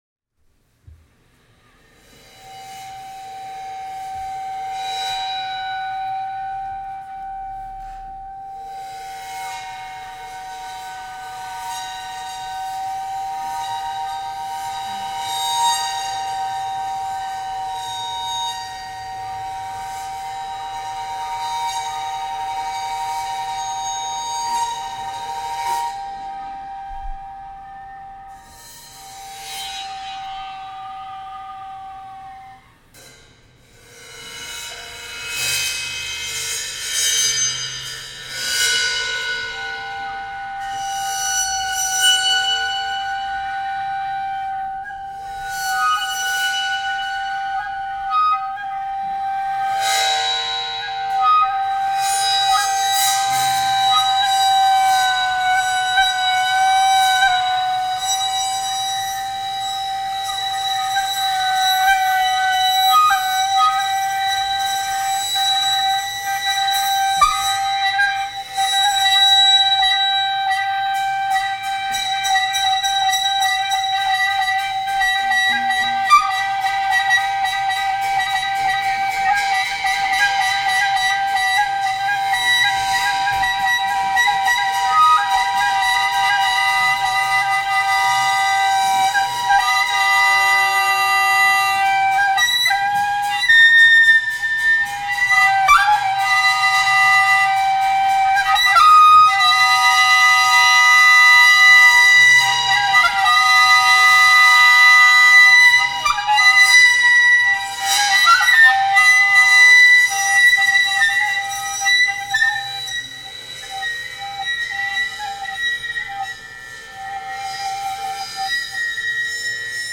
Live at 65 Fen